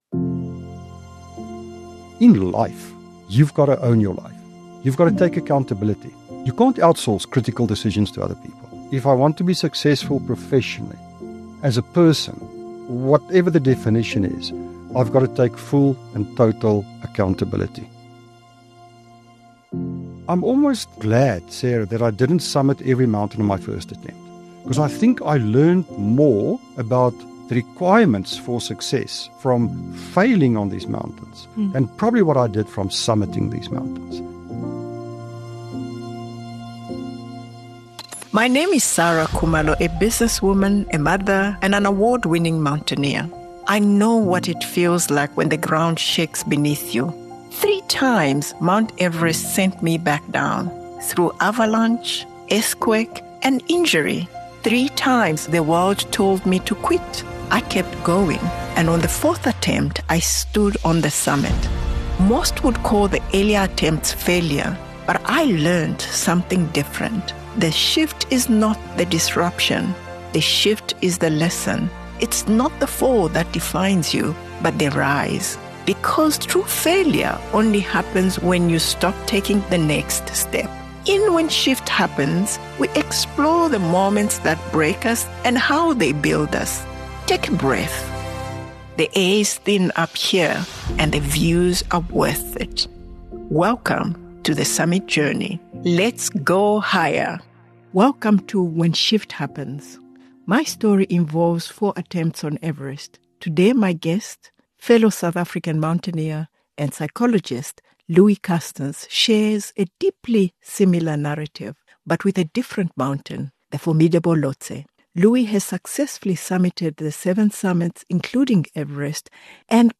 It’s a conversation about what it really takes to keep moving forward when things do not go to plan.